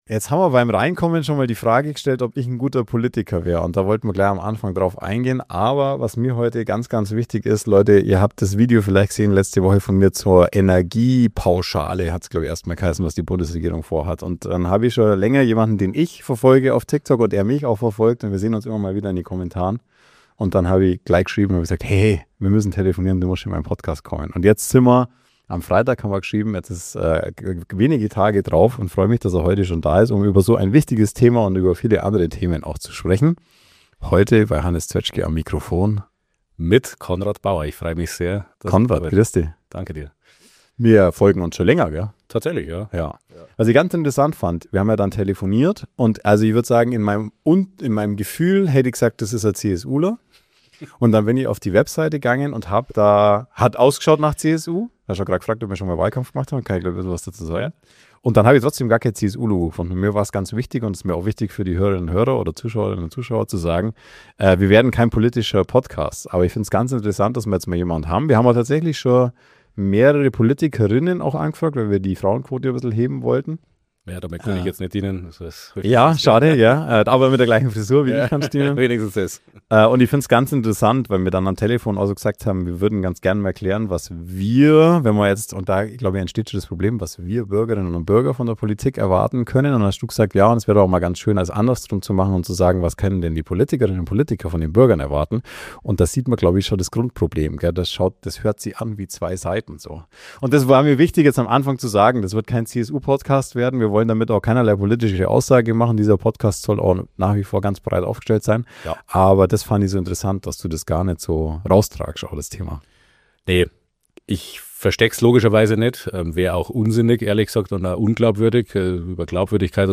Heute habe ich jemanden zu Gast, den ich über TikTok kennengelernt habe. Konrad Baur ist heute Berufspolitiker und Landtagsabgeordneter.